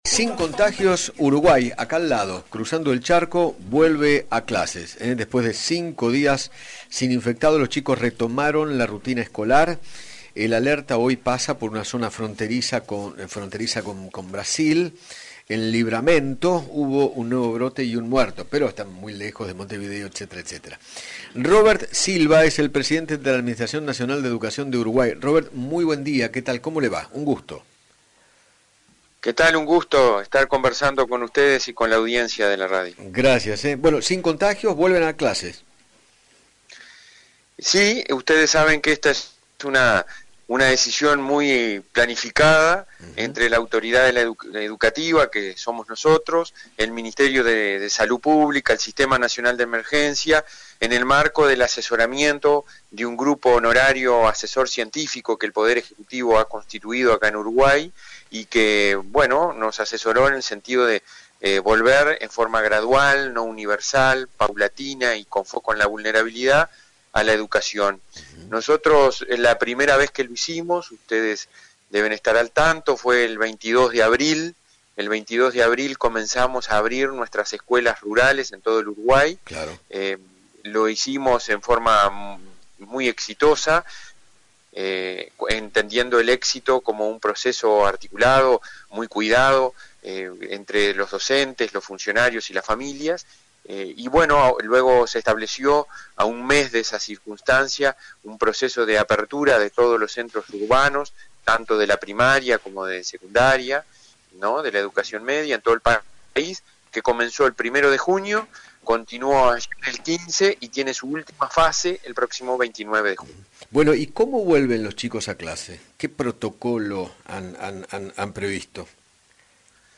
Robert Silva, Presidente de la Administración Nacional de Educación de Uruguay, dialogó con Eduardo Feinmann sobre la vuelta de las clase en el país vecino, después de cinco días sin casos de Coronavirus, y explicó cómo es el protocolo.